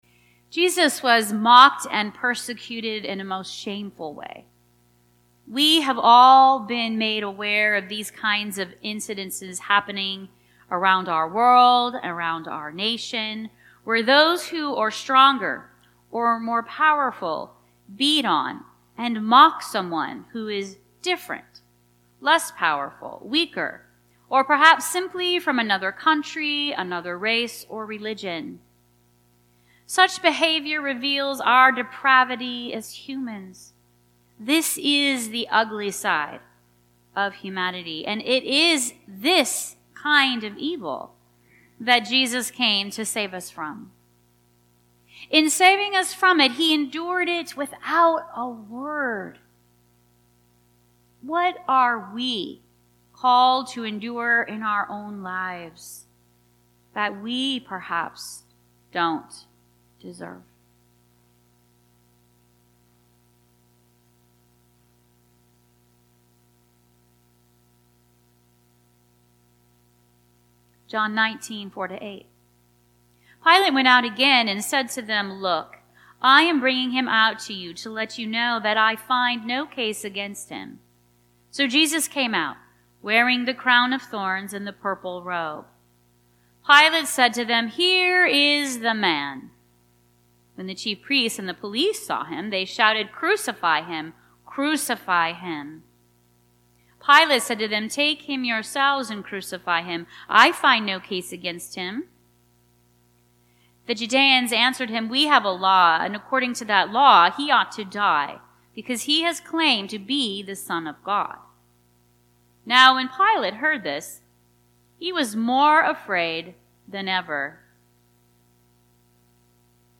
Listen to our recorded sermons in high-quality .mp3 format.